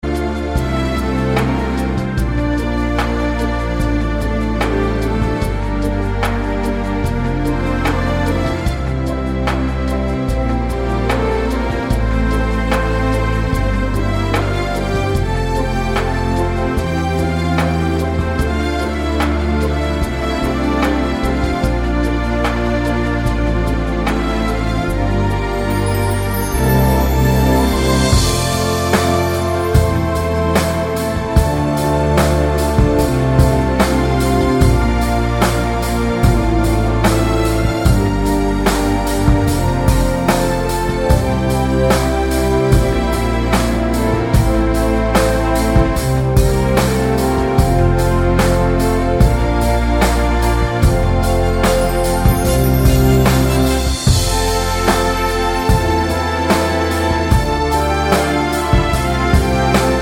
no Backing Vocals Easy Listening 3:37 Buy £1.50